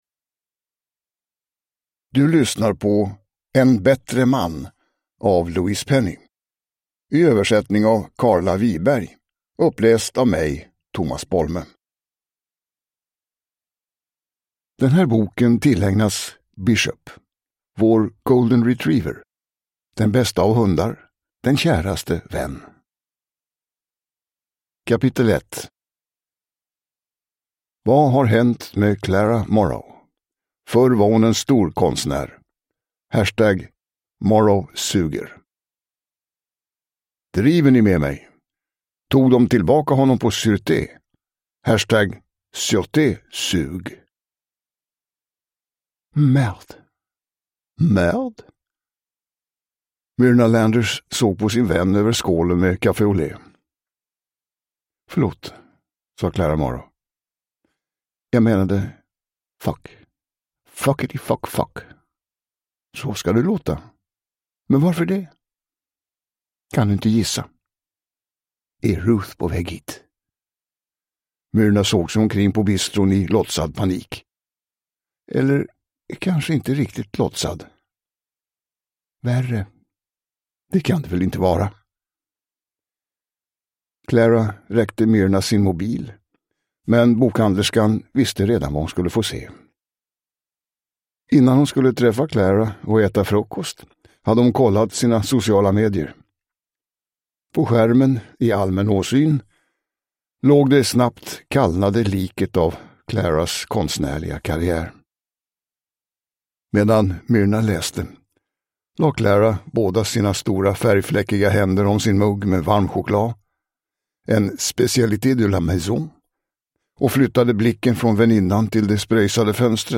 Uppläsare: Tomas Bolme
Ljudbok